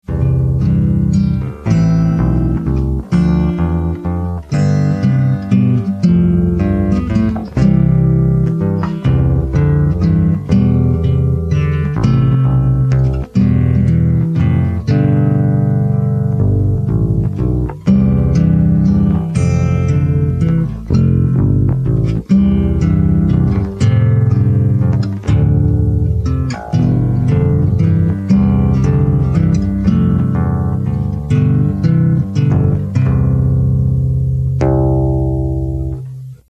As far as I remember, I recorded this on two tracks – acoustic guitar on one, and acoustic bass guitar on the other.
This song took me a fair bit of practice, especially the guitar part, as I tried to play it note-for-note perfect out of the hymnal, the top 3 notes of each chord on the guitar, and obviously the bass note on the acoustic bass. I’m pretty happy with the results, though the last note is pretty goofy.